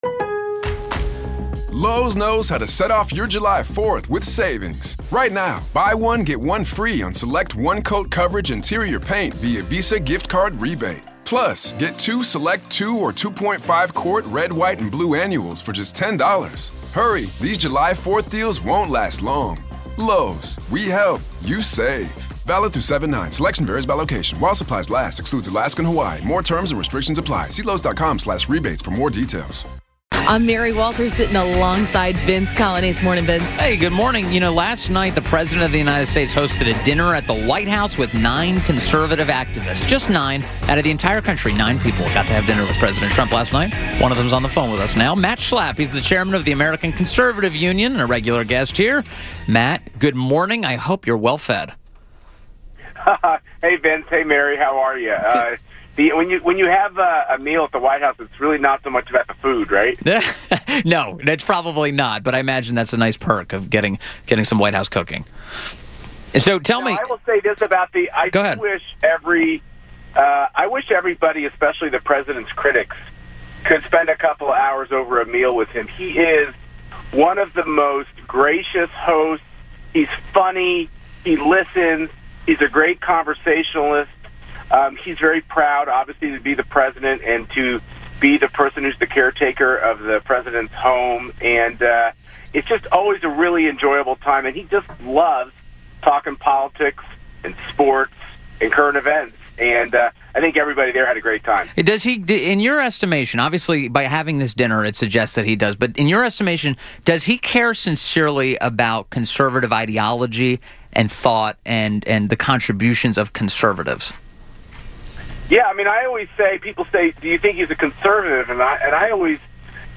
WMAL Interview - MATT SCHLAPP - 09.26.17